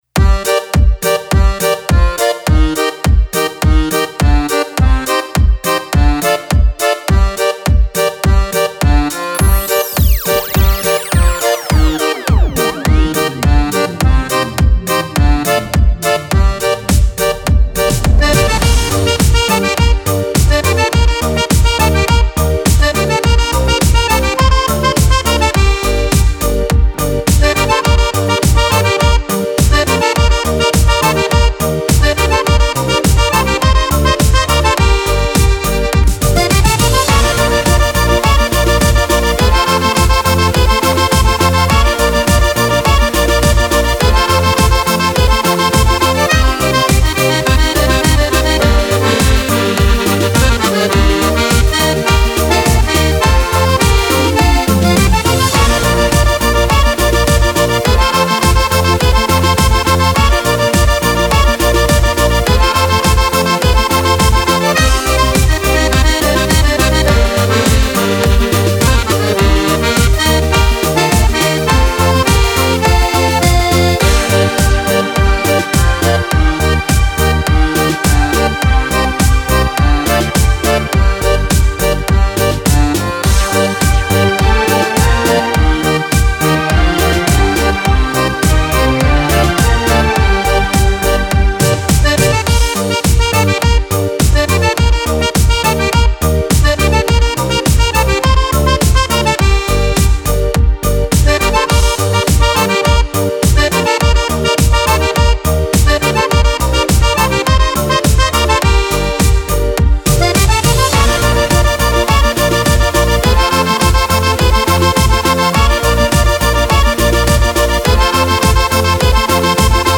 Cumbia per Fisarmonica